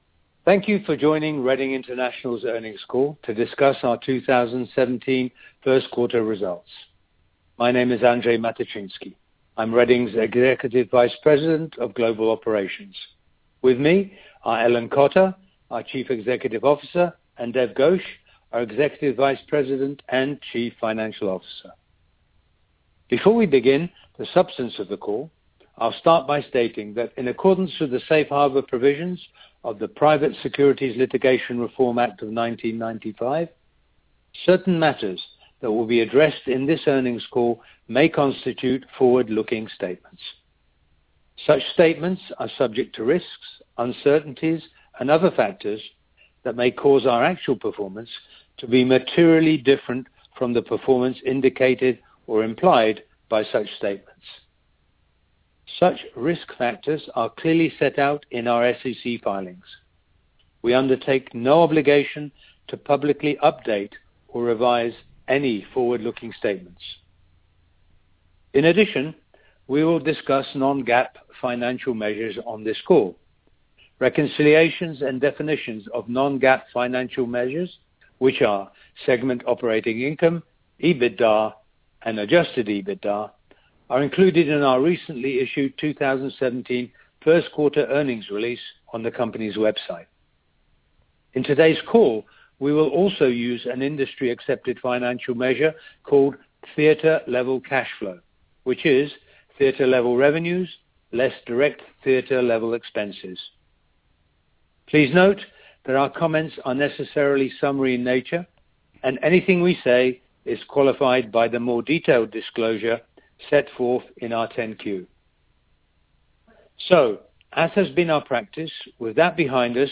Question and answer session will follow the formal remarks.